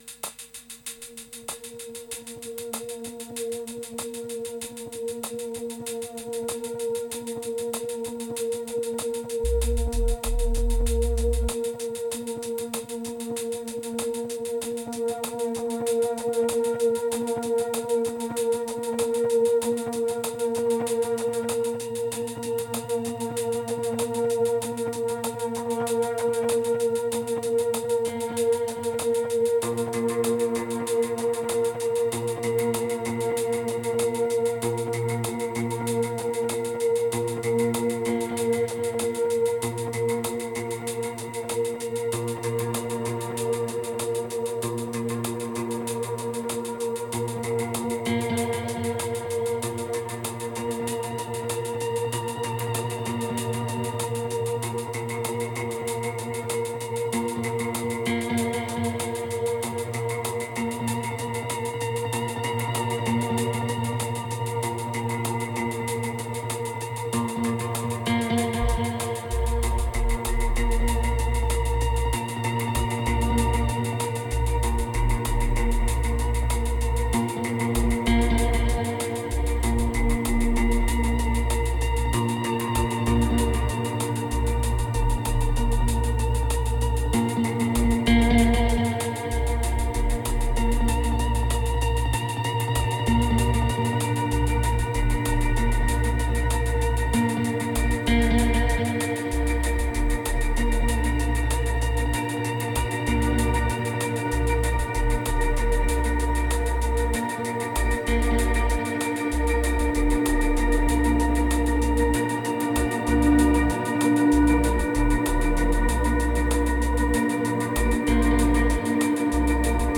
2547📈 - 95%🤔 - 48BPM🔊 - 2023-02-13📅 - 1316🌟